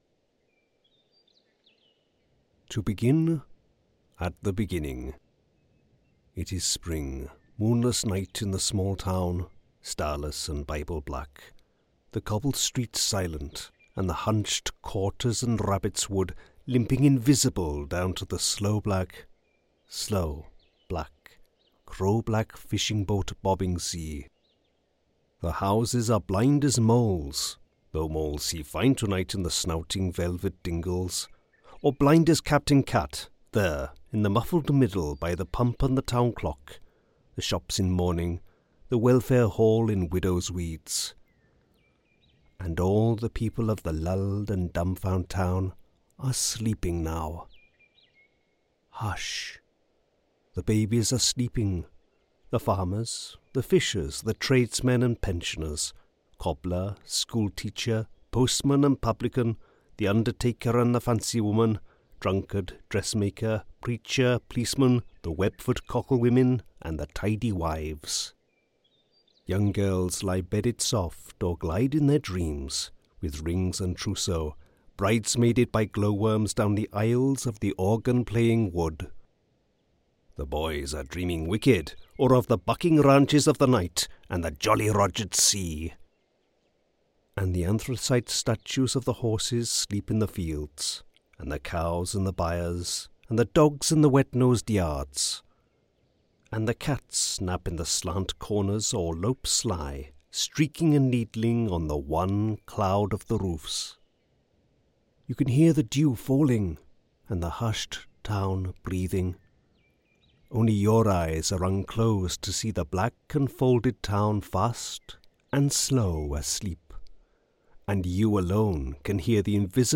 Male
English (British)
Audiobooks
Welsh Accent, Under Milk Wood